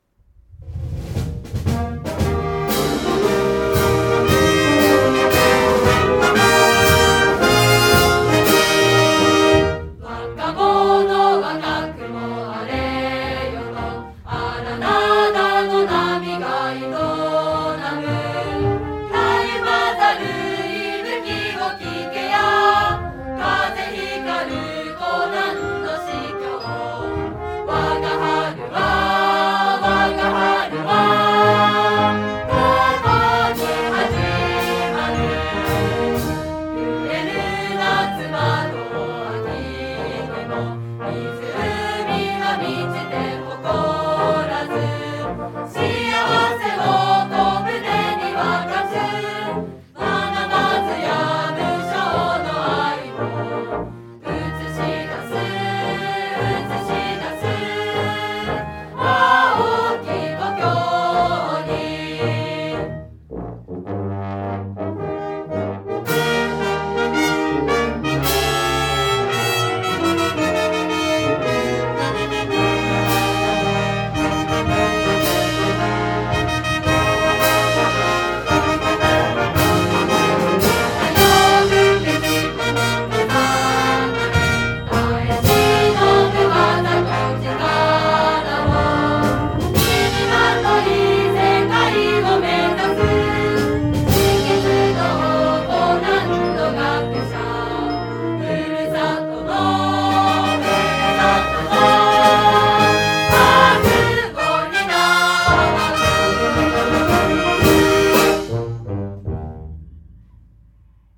作成者 見崎泰中氏、補作者 林竹計氏、昭和58年４月４日制定 校歌 本校の校歌は星野哲郎氏が作詞し、鏑木創氏が作曲したものです。